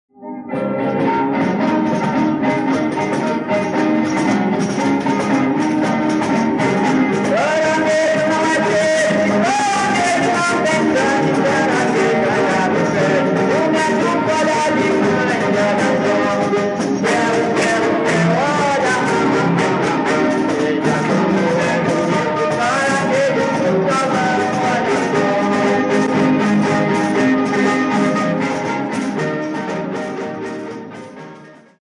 Dança de pares com formação em círculo. Em ritmo leve (valseado) os pares se posicionam frente a frente, os cavalheiros do lado de fora do círculo segurando as mãos de seus pares.
autor: Ciranda de Paraty., data: 1975.